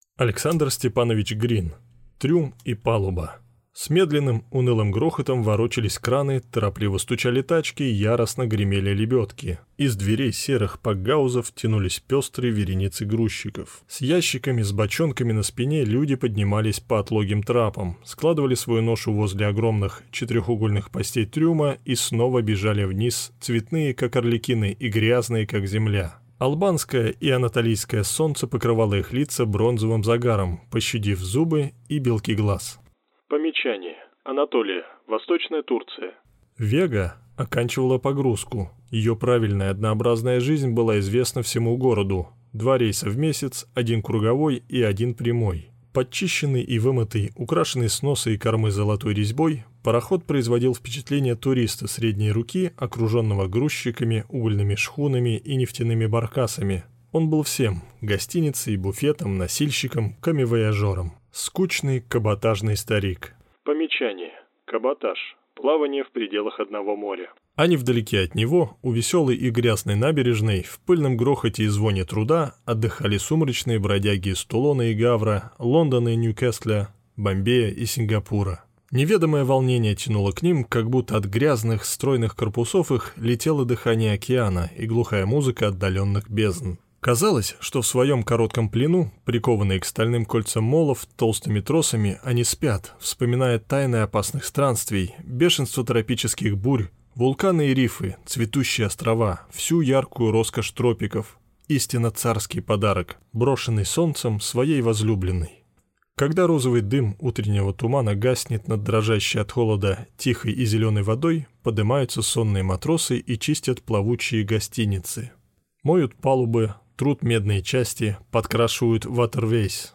Аудиокнига Трюм и палуба | Библиотека аудиокниг